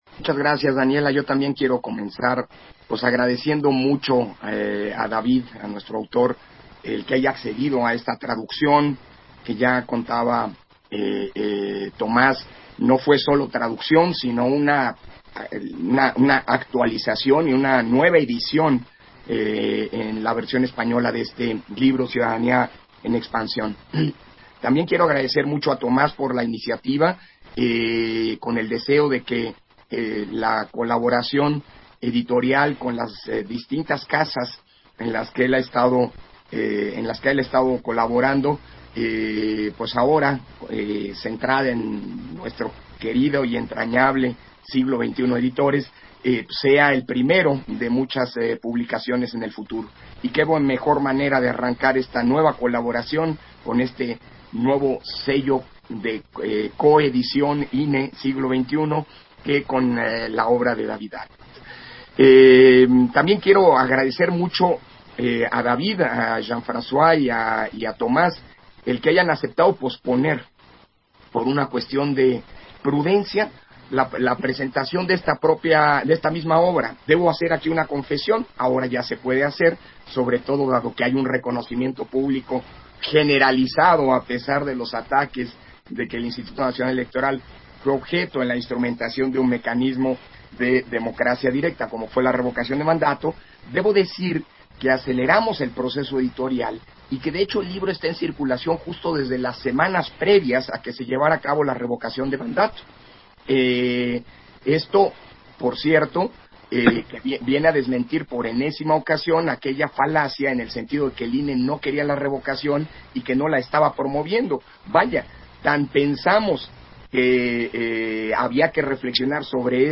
290622_AUDIO_INTERVENCIÓN-CONSEJERO-PDTE.-CÓRDOVA-PRESENTACIÓN-CIUDADANÍA-EN-EXPANSIÓN - Central Electoral